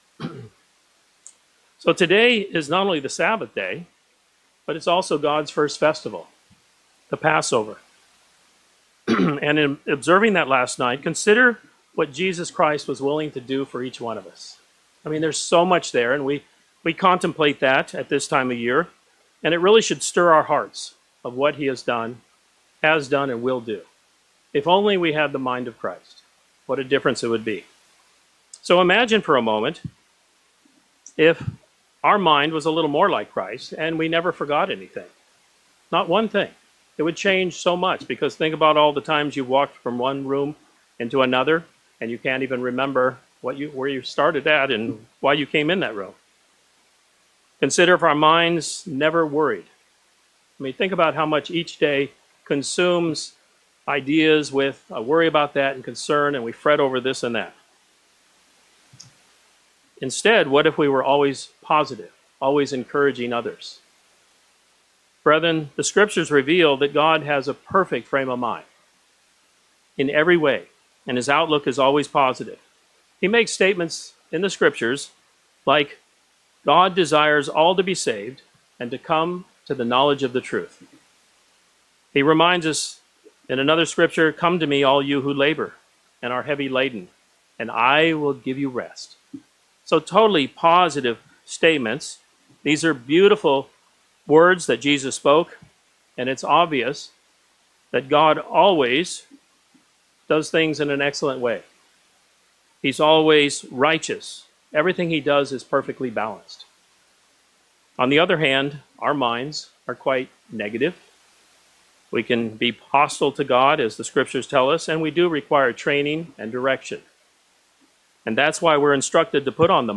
This sermon focuses on two vital questions: 1). Is my humility acceptable to God? 2).